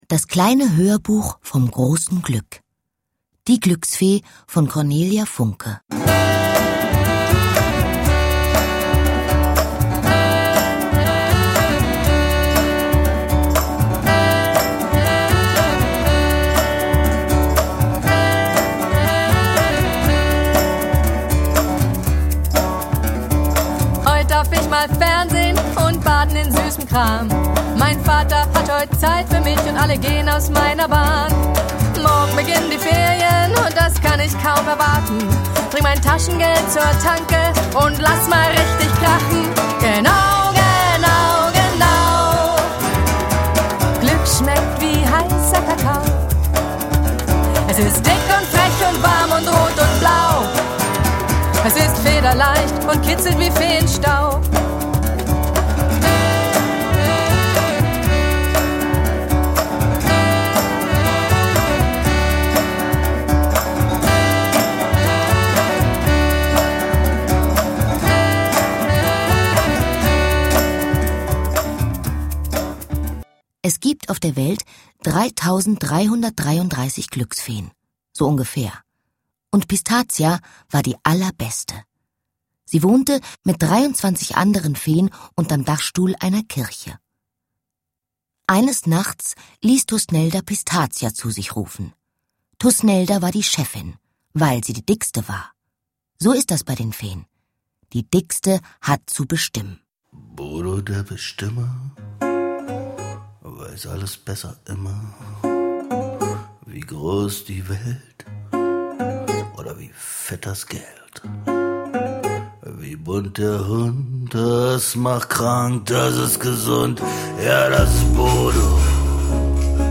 Anna Thalbach (Sprecher)
Mit vielen tollen Songs zum Mitsingen für kleine und große Leute!
Mit ihrer ausdrucksstarken Stimme ist sie eine der gefragtesten Hörbuchsprecherinnen.